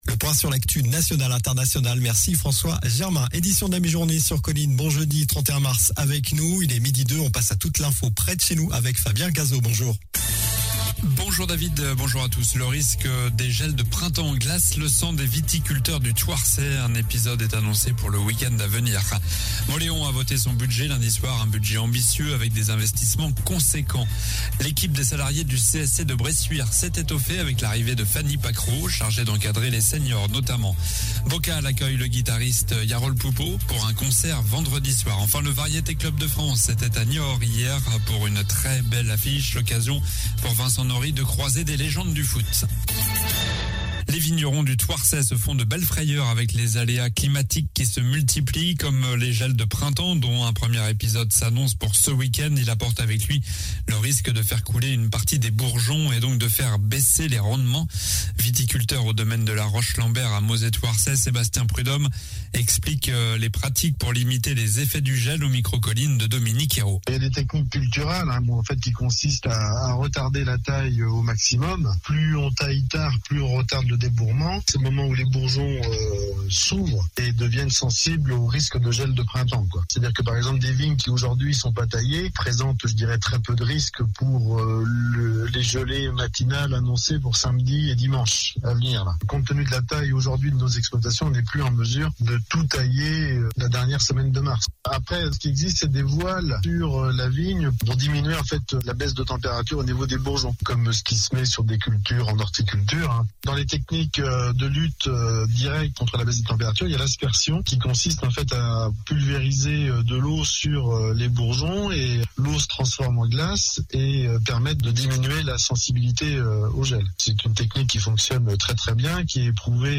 Journal du jeudi 31 mars (midi)